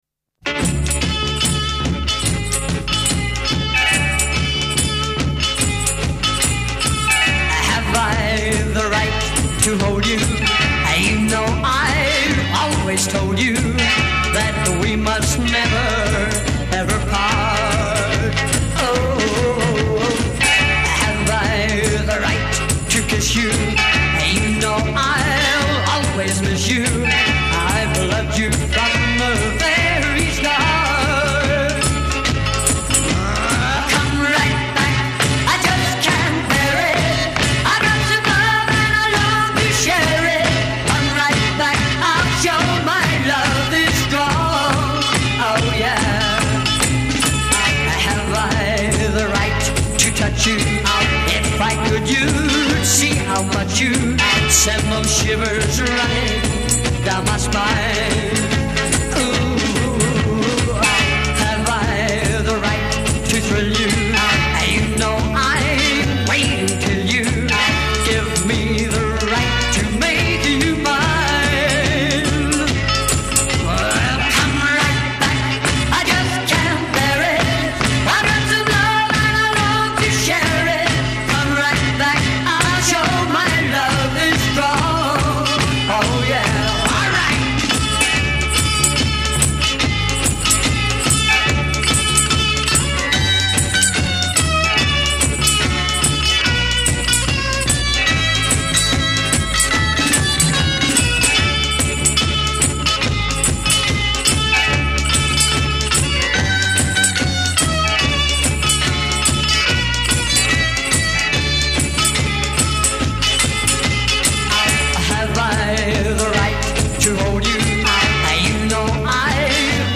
lead vocal
lead guitar
rhythm guitar
drums
bass
Intro 0:00 4 either double tracked or doubled guitar.
8 double-tracked voice
A'1-2 verse : 16 double-tracked or doubled guitar solo